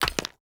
Chopping and Mining
mine 1.wav